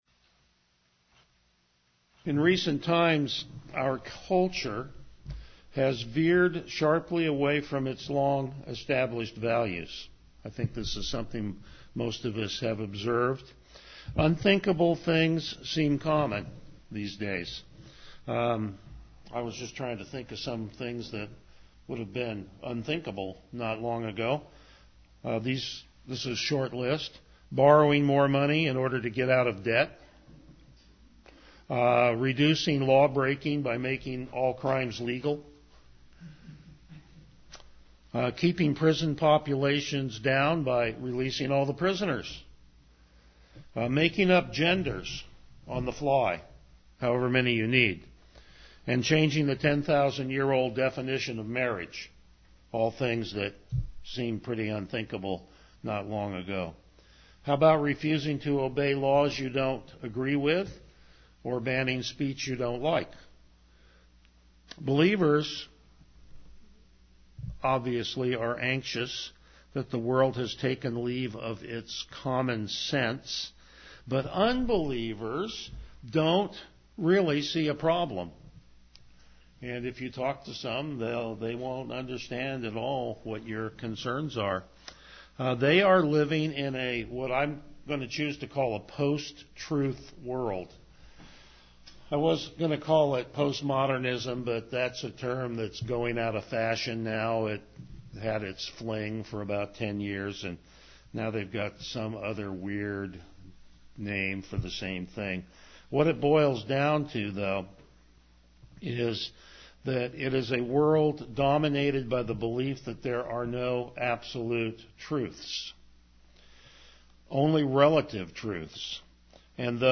The Book of Hebrews Passage: Hebrews 1:1-3 Service Type: Morning Worship Topics